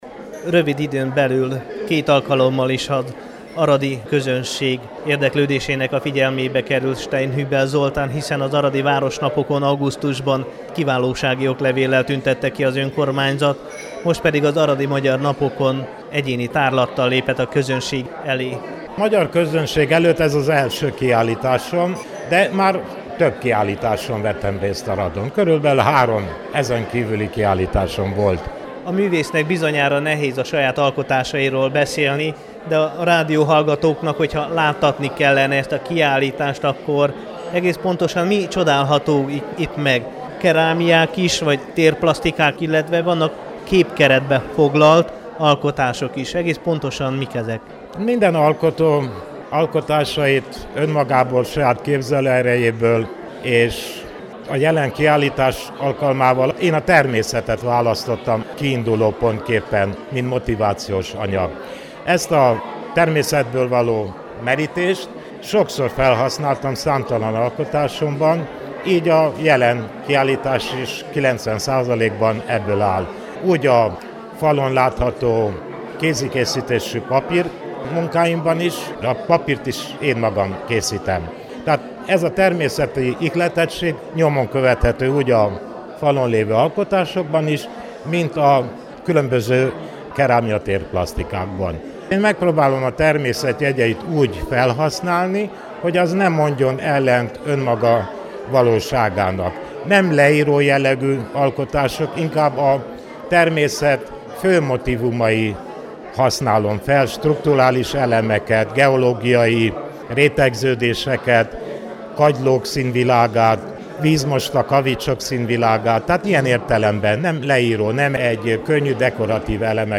Szerda este nyitották meg az Aradi Magyar Napok keretében a Kölcsey Képtár 8. kiállítását.